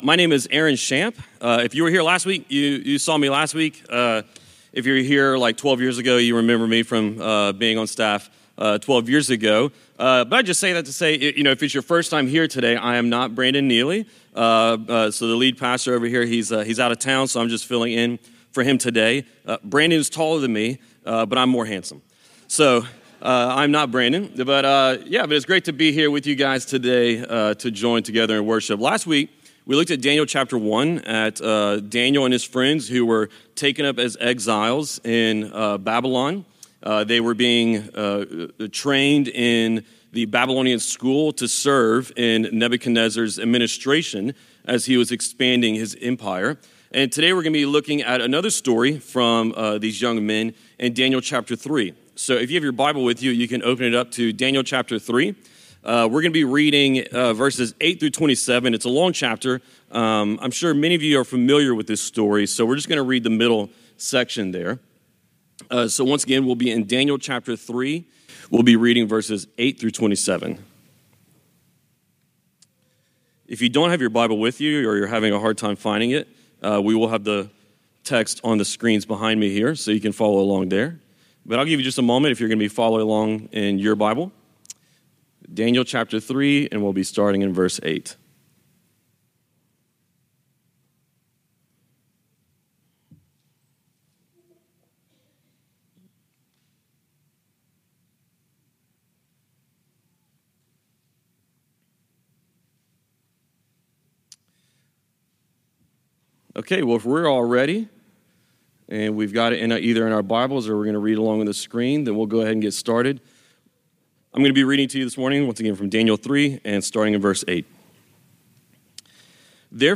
This is a part of our sermons.